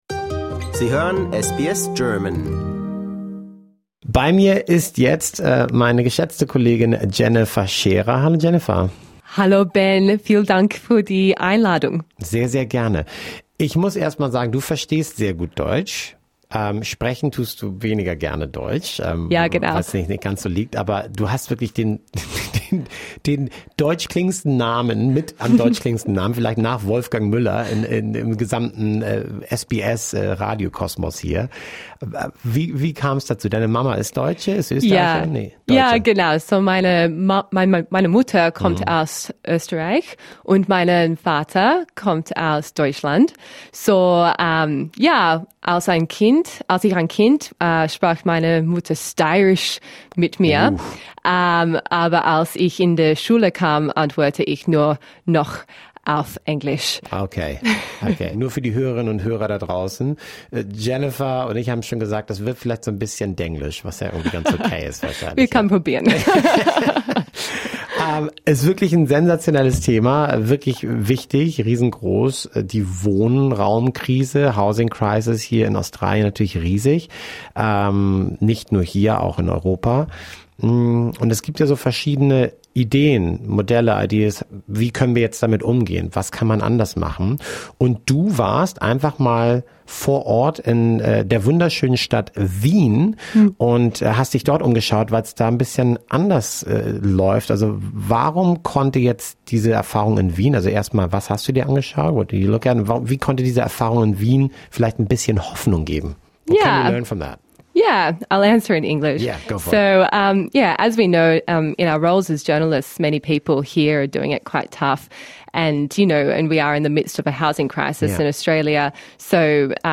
In an interview, she explains what is behind the Vienna housing model — and whether countries like Australia could learn from it.